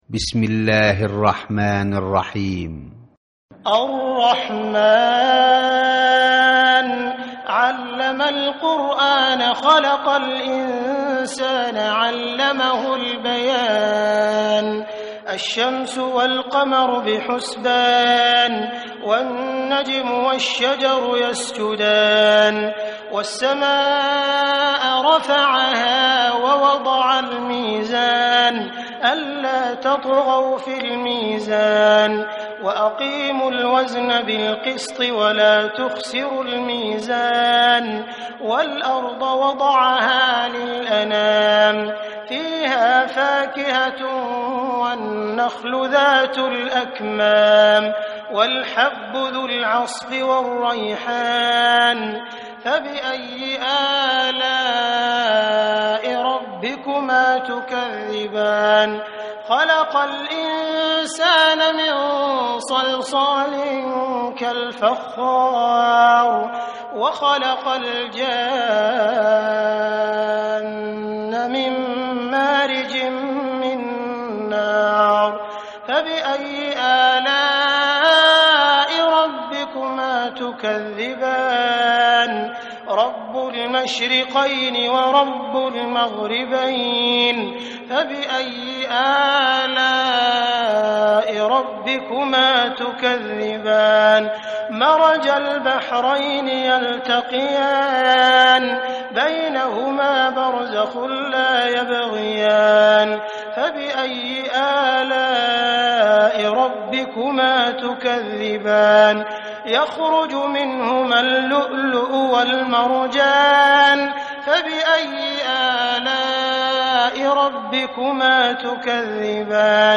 récitation mp3 par Hafidh Abd er-Rahmân Sudais (qdlfm) - 3 601 ko ;